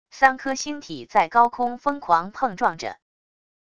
三颗星体在高空疯狂碰撞着wav音频